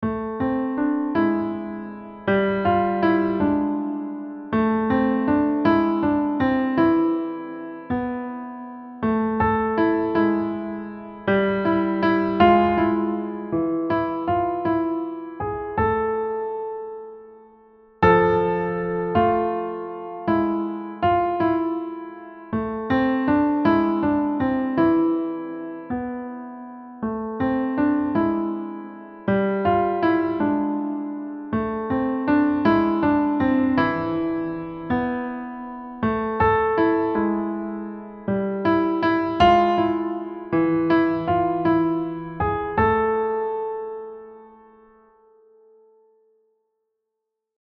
Time signature: 3/4, BPM ≈ 160 (waltz-like feel)
• Left-hand repeating chromatic accompaniment figures
• Feeling a waltz “in 1” and shaping musical phrases